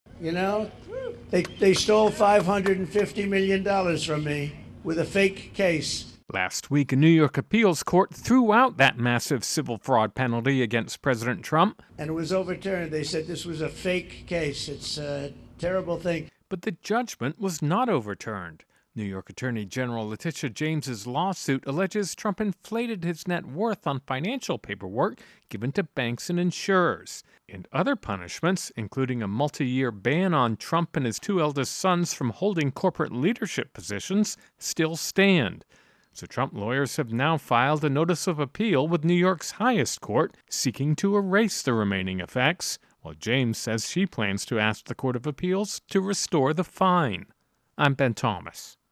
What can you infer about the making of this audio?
((opens with actuality))